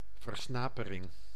Ääntäminen
France: IPA: [yn fʁi.jã.diz]